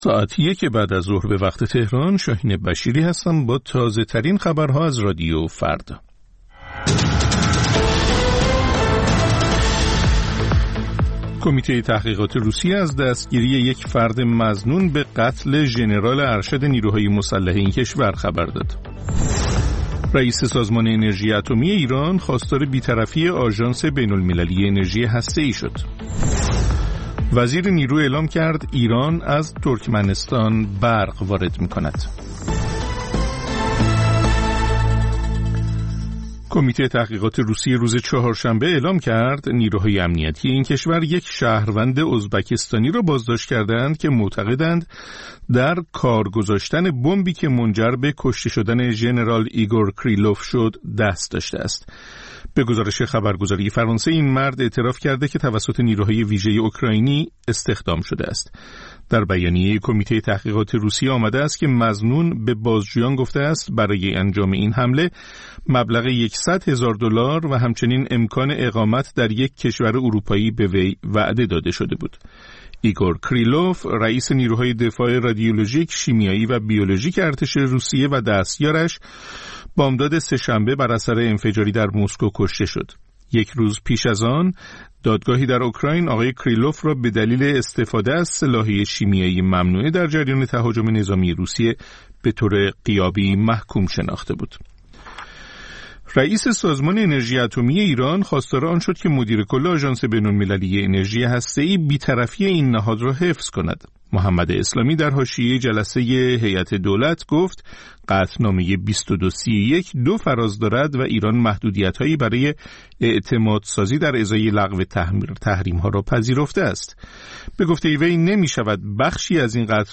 سرخط خبرها ۱۳:۰۰